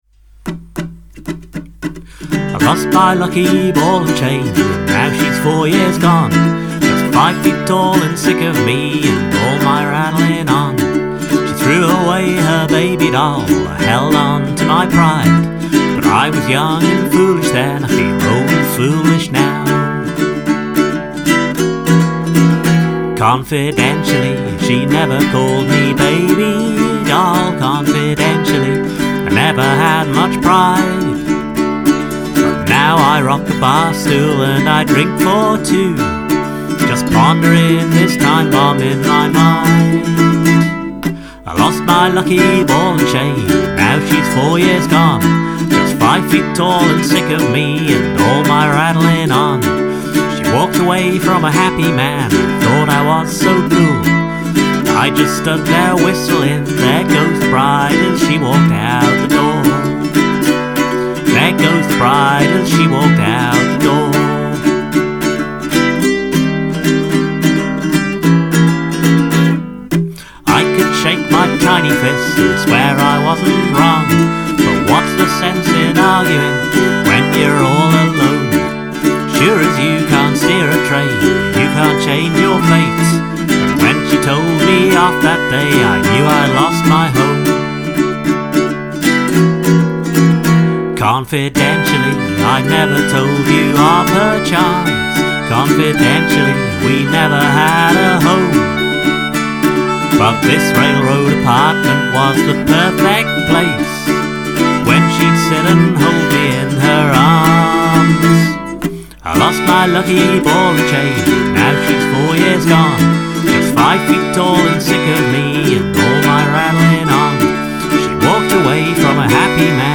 Great uke sound and then the Concertina? kicks in…so cool .